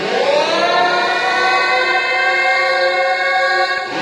sweepSiren.ogg